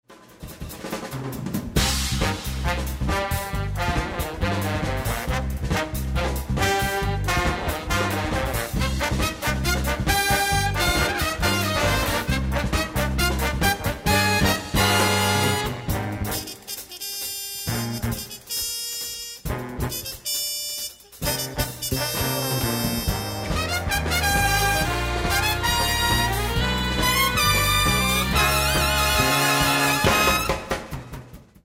the applause and whistles and clinking of glasses.
A captivating, infectious original piece
trumpet